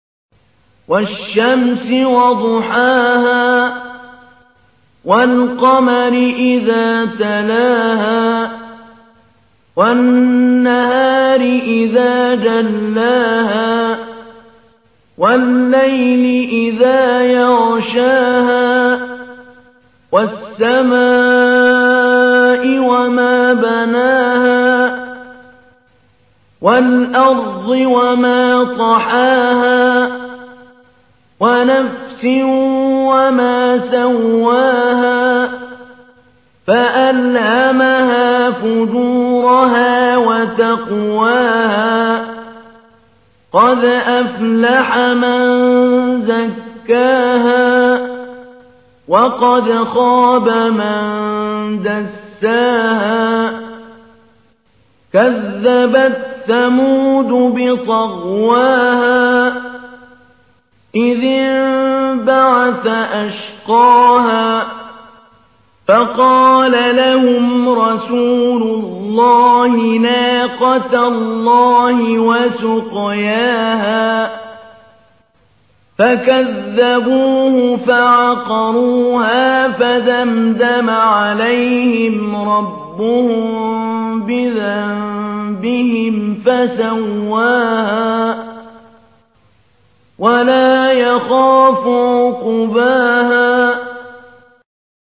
تحميل : 91. سورة الشمس / القارئ محمود علي البنا / القرآن الكريم / موقع يا حسين